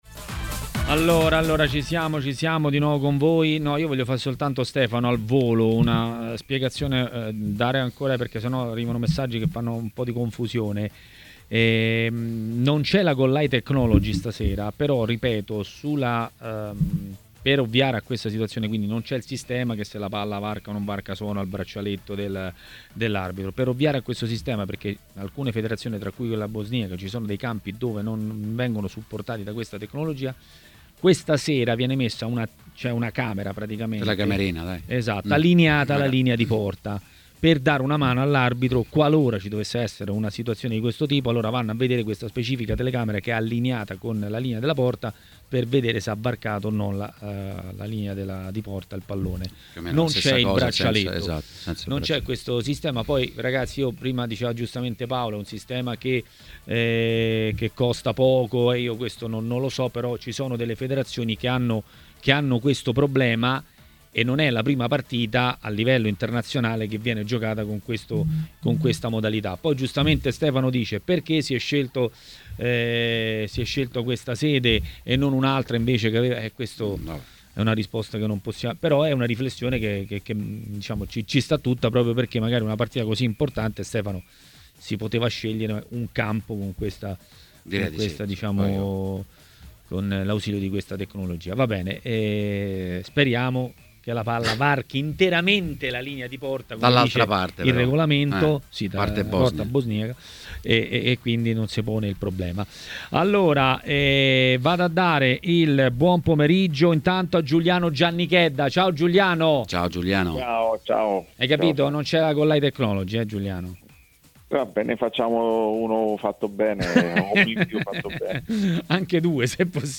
L'ex calciatore David Di Michele è stato ospite di Maracanà, trasmissione del pomeriggio di TMW Radio.